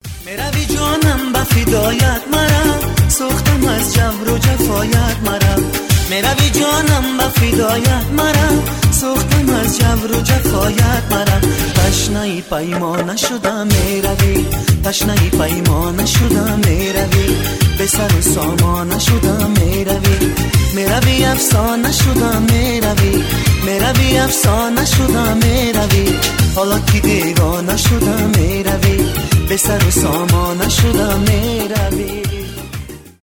таджикские , танцевальные